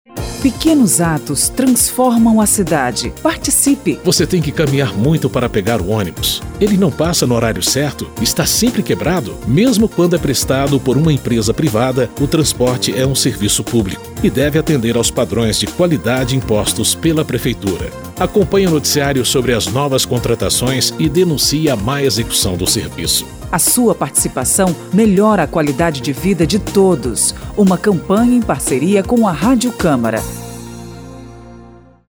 São 7 spots de 30 segundos sobre saúde, transporte, educação e segurança, destacando o papel de cada um – prefeito, vereadores e cidadãos – na melhoria da vida de todos.
spot-pequenos-atos-2.mp3